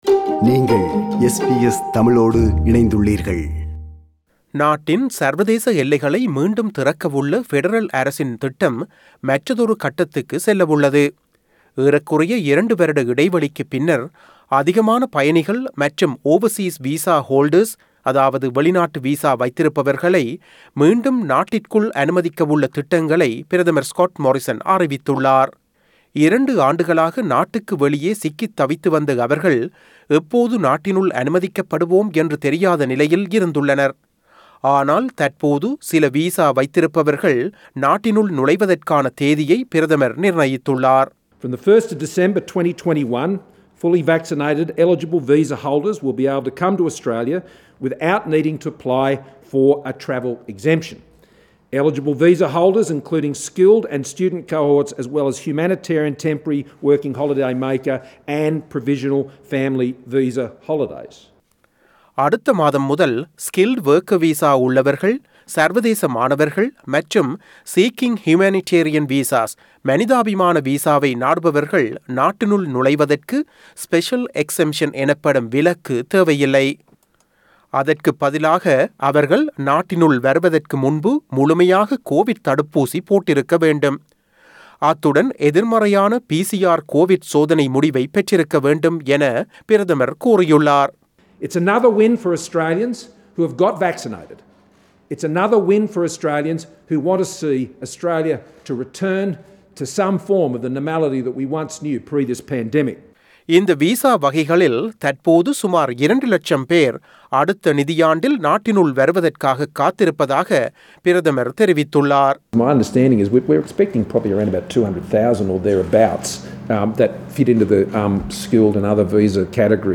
செய்தி விவரணத்தை தமிழில் தருகிறார்